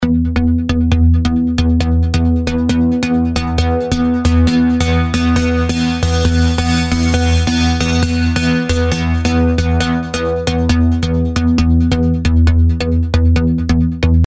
恍惚的Sq第5个Bline Cutoff向上和向下。
描述：同样的恍惚低音线，有一个截止的增加和减少。
Tag: 135 bpm Trance Loops Bass Loops 2.39 MB wav Key : Unknown